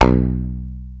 32. 32. Percussive FX 31 ZG